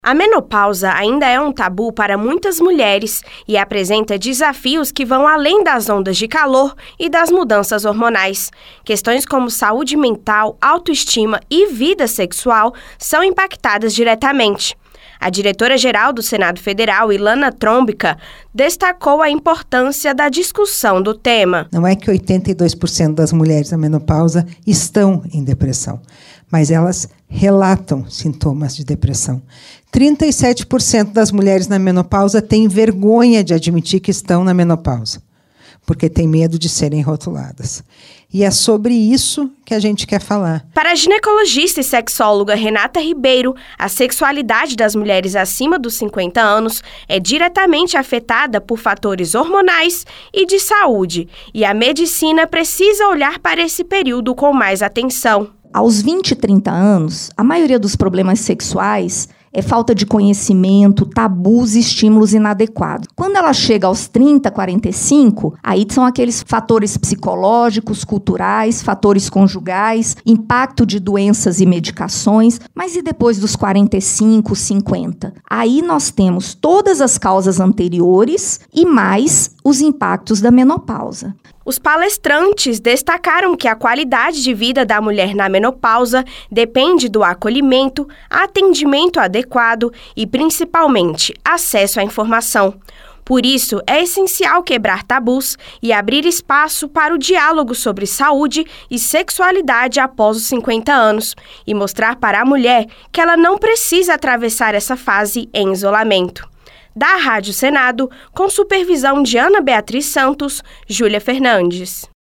Rádio Senado : Notícias : 2025.